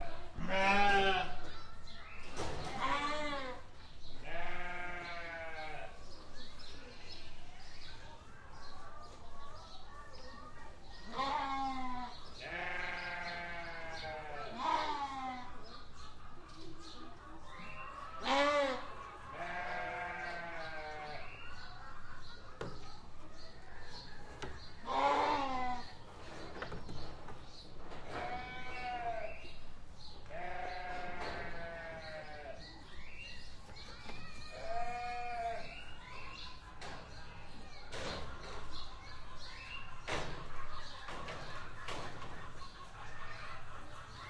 Cefn Mably农场的声音 " 绵羊牧场
描述：在南威尔士的Cefn Mably农场，一个宠物动物园进行录音。将NT4放在飞艇上，放在Tascam DR680上
Tag: 咩咩 农场 牧场 威尔士